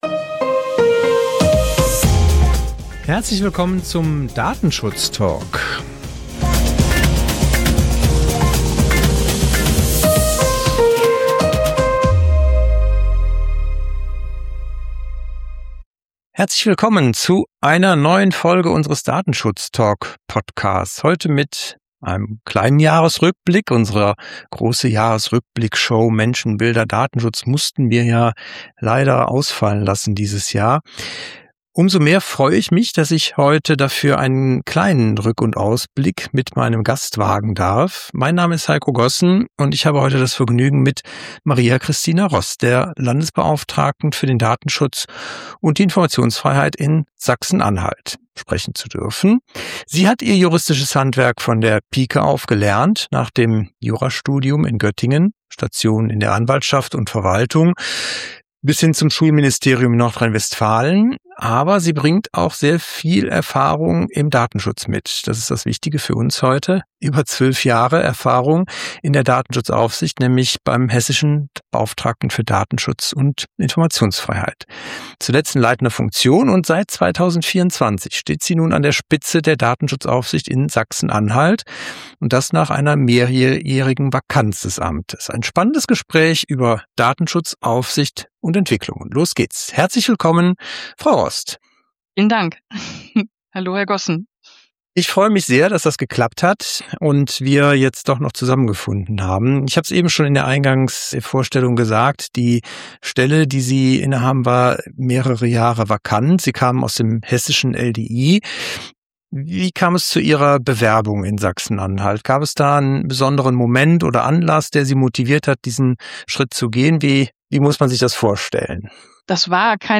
Nach mehreren Jahren Vakanz hat Maria Christina Rost die Datenschutzaufsicht in Sachsen-Anhalt neu aufgestellt. Im Gespräch berichtet sie offen über ihren Weg ins Amt, die politischen Auswahlprozesse, strukturelle Herausforderungen in der Behörde und darüber, was es bedeutet, eine Datenschutzaufsicht nach außen wieder sichtbar zu machen.